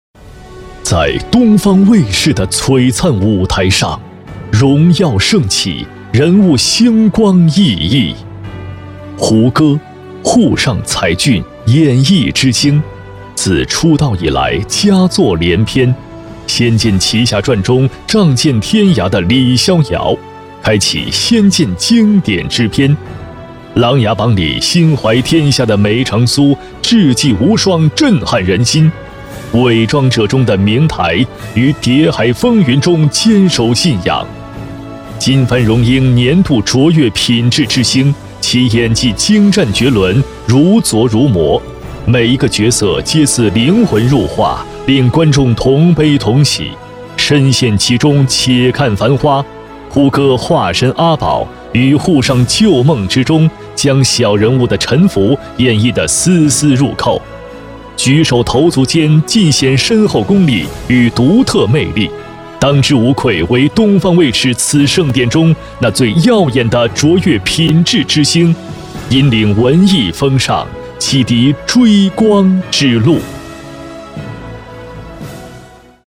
男264浑厚大气-深度配音网
国语配音
男264-激情颁奖-东方卫视颁奖典礼-明星.mp3